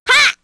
Hanus-Vox_Damage_01_kr.wav